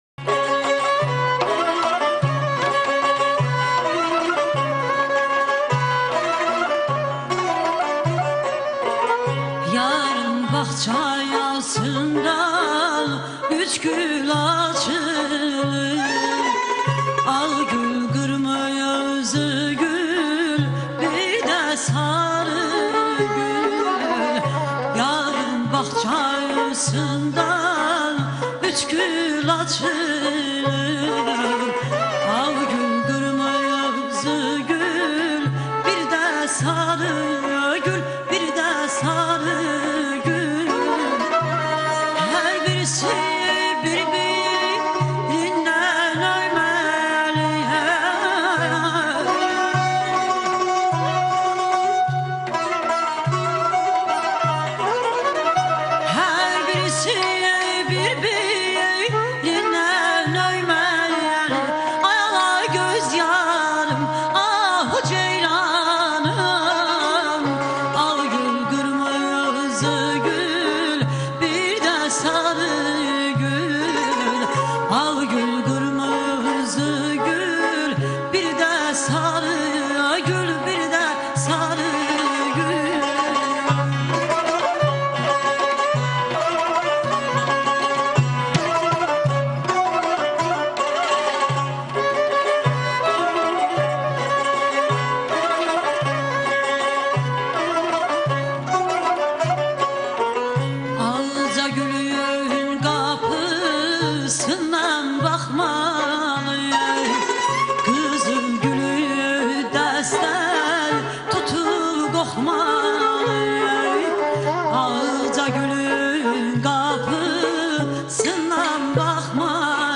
Azerbaycan xalq mahnı ve tesnifleri
دستگاه : شور | Şur